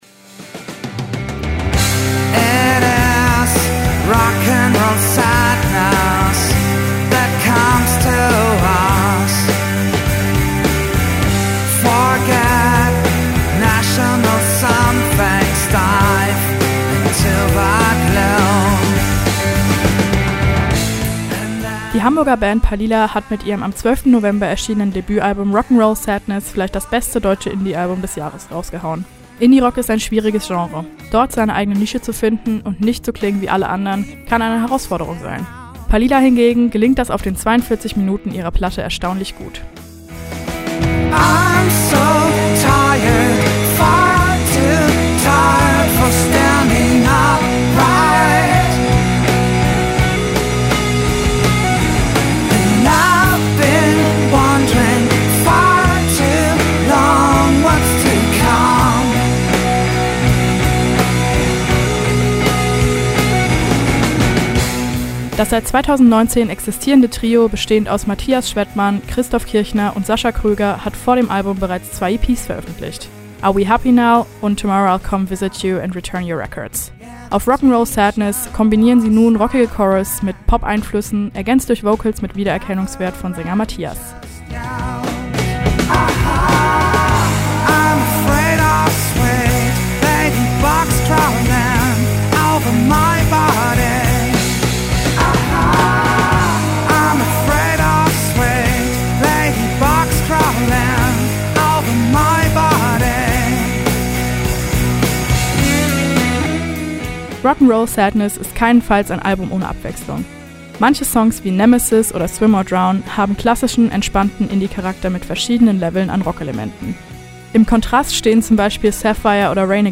kombinieren sie nun rockige Chorus mit Pop-Einflüssen